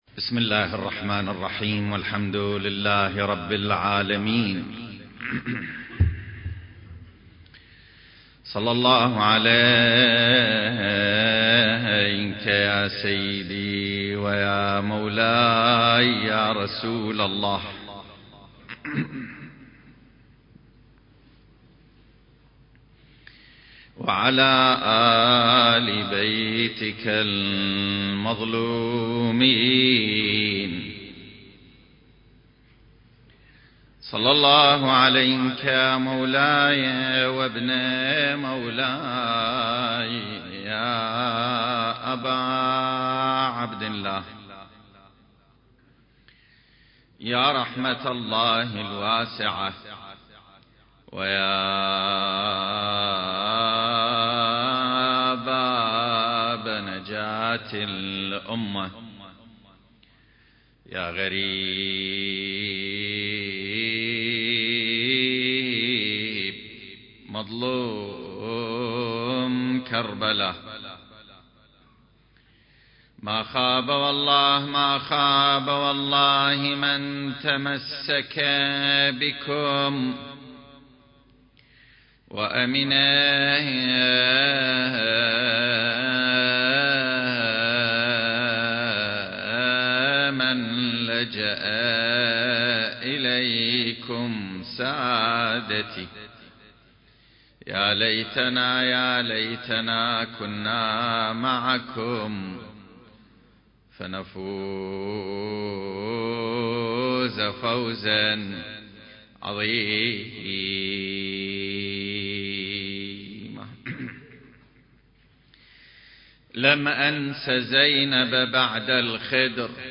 سلسلة محاضرات: نفحات منبرية في السيرة المهدوية المكان